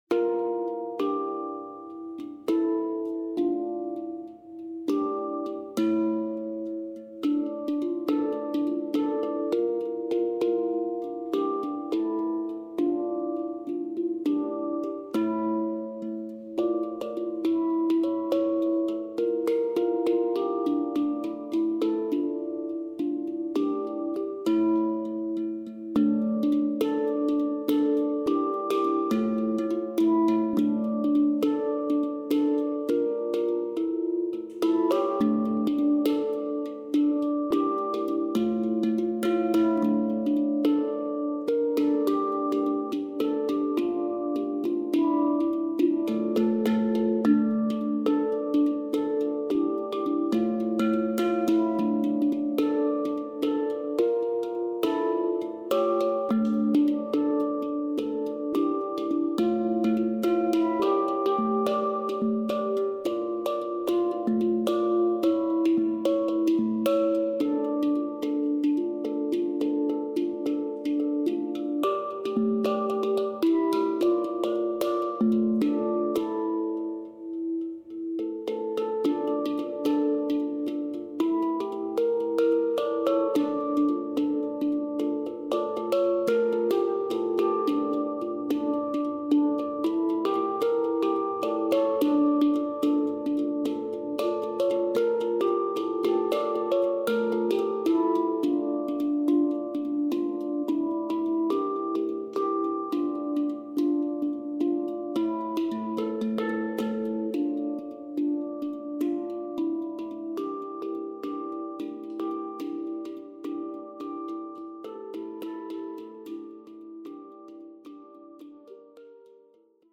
solo Hang